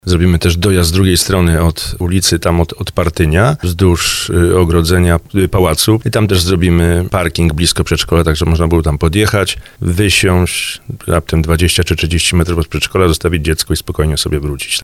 Burmistrz Żabna Tomasz Kijowski mówi Radiu RDN Małopolska, że o tej sytuacji dowiedział się trzy miesiące temu. Jak zapewnia, problem zostanie rozwiązany.